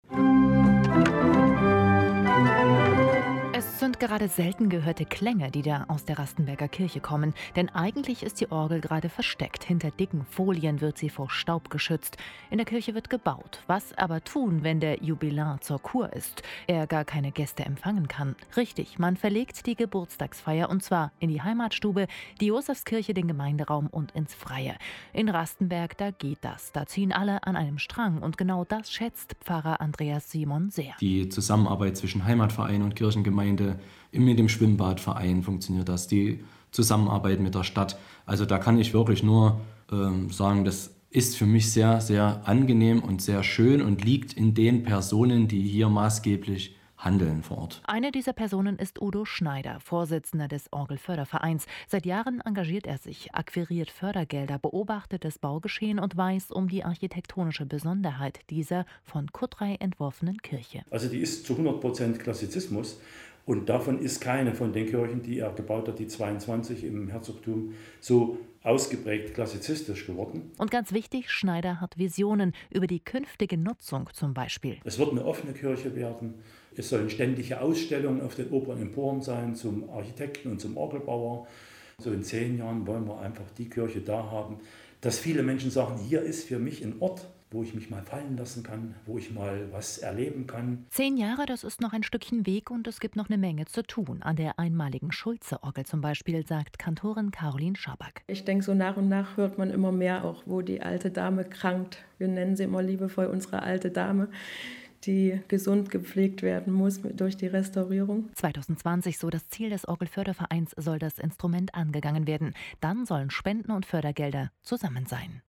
Hörfunkbeitrag Festwoche 190 Jahre Stadtkirche
Zu unserer Festwoche 190 Jahre Stadtkirche im Dezember 2016 wurde ebenfalls ein Hörfunkbeitrag erstellt.